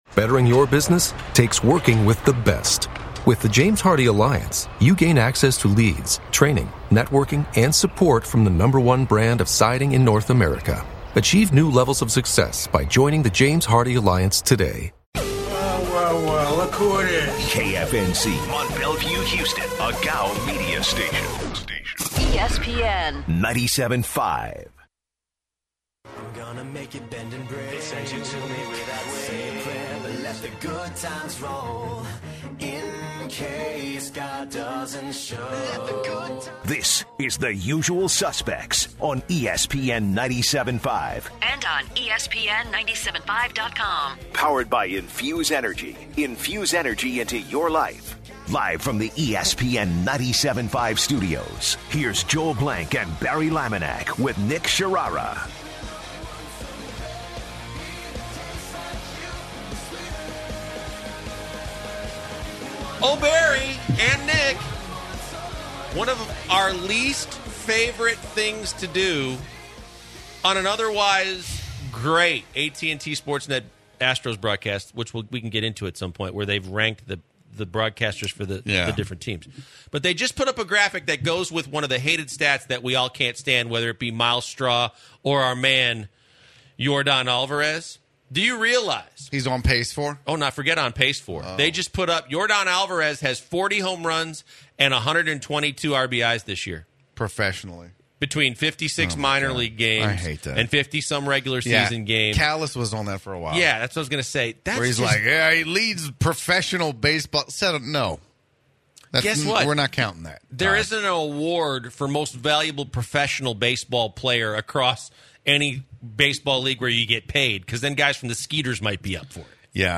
The guys kick off hour two discussing the baseball’s best broadcast teams. Then hear live reactions of the Astros VS. White Sox game.